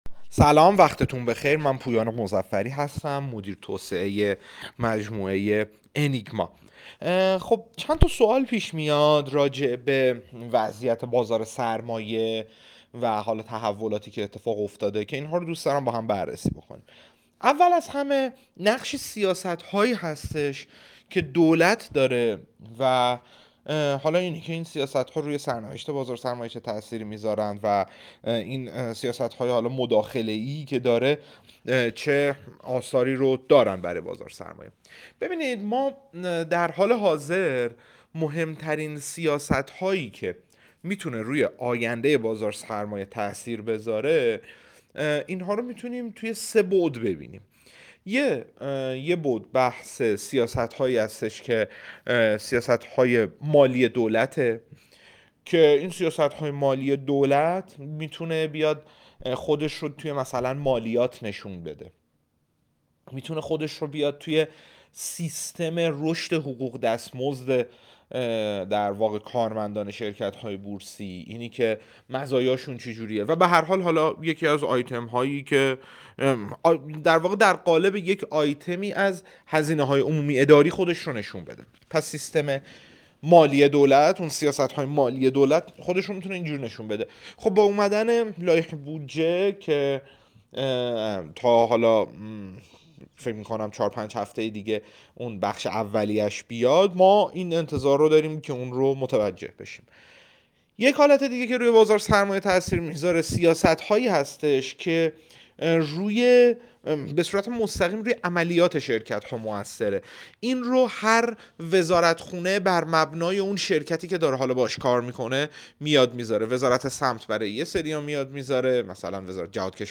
در گفت‌وگو با بورس‌نیوز